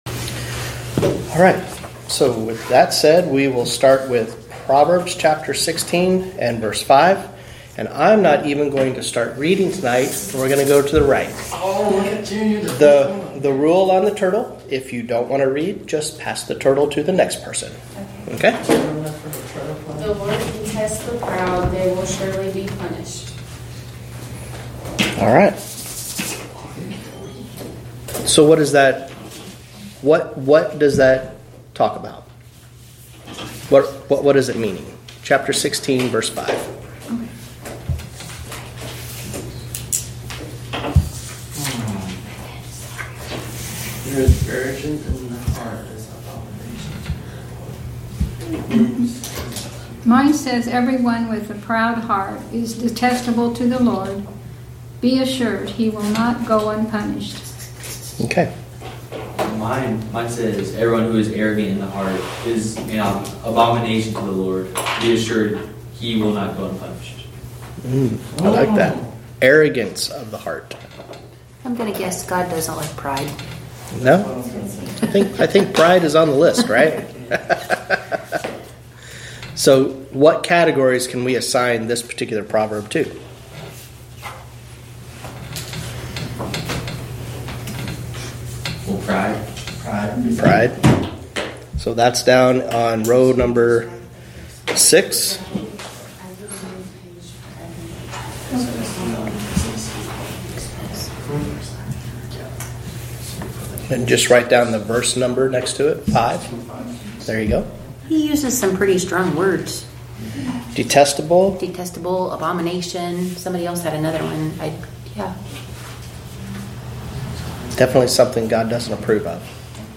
Wednesday Evening Bible Study Proverbs 16:5-20